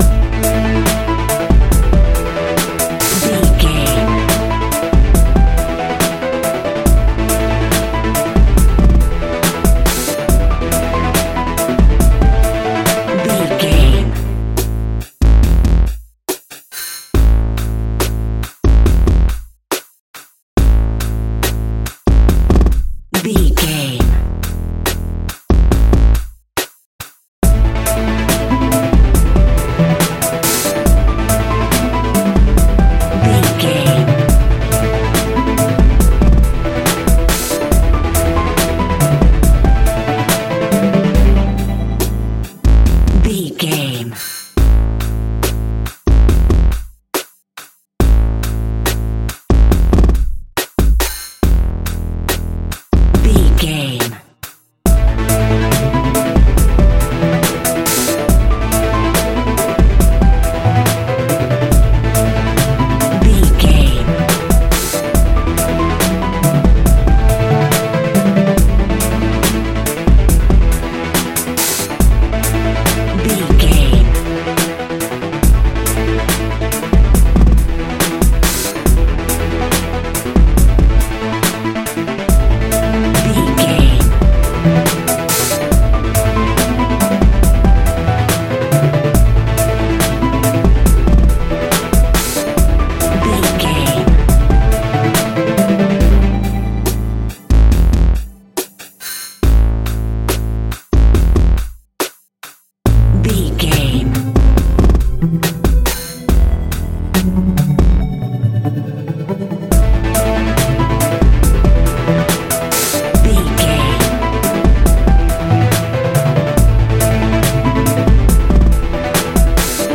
Aeolian/Minor
Fast
Funk
electronic
drum machine
synths